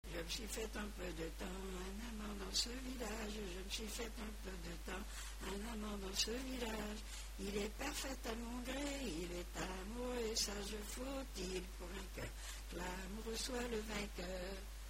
Genre laisse